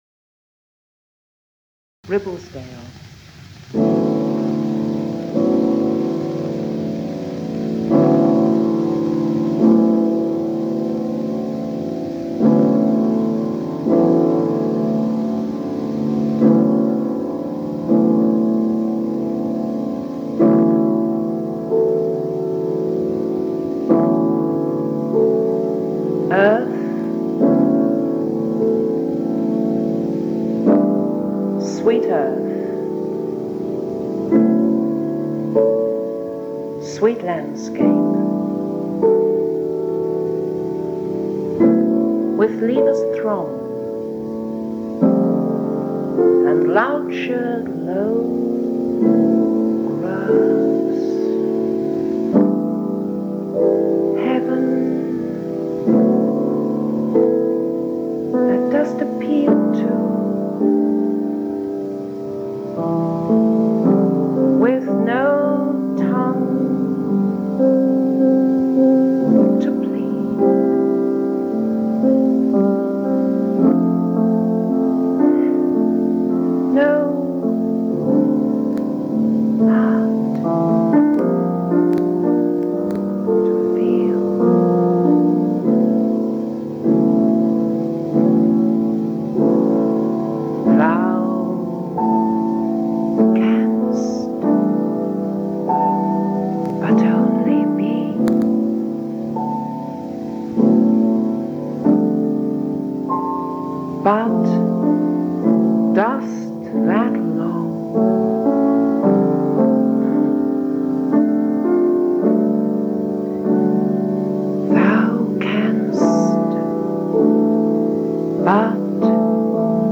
2. jazz/poetry fusion